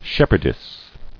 [shep·herd·ess]